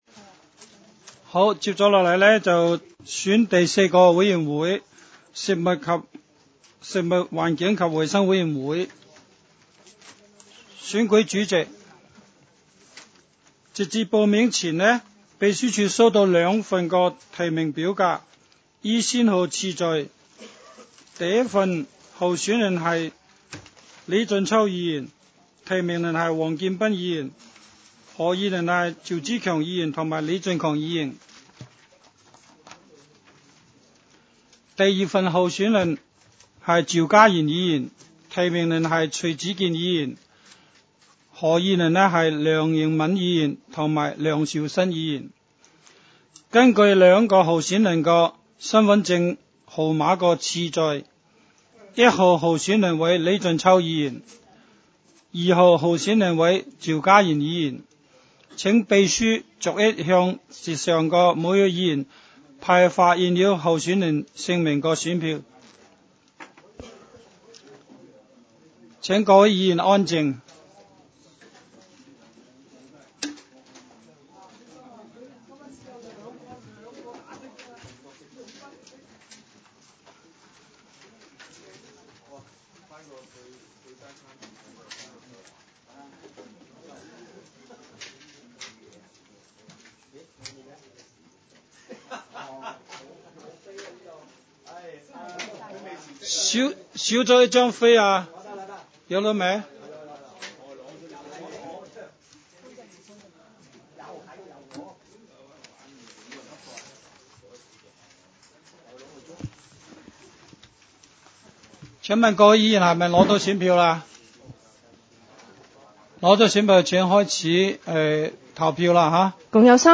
委员会会议的录音记录
食物、环境及卫生委员会第一次会议 日期: 2018-01-02 (星期二) 时间: 下午3时30分 地点: 香港西湾河太安街 29 号 东区法院大楼 11 楼东区区议会会议室 议程 讨论时间 I 推选委员会主席及副主席 0:14:12 全部展开 全部收回 议程:I 推选委员会主席及副主席 讨论时间: 0:14:12 前一页 返回页首 如欲参阅以上文件所载档案较大的附件或受版权保护的附件，请向 区议会秘书处 或有关版权持有人（按情况）查询。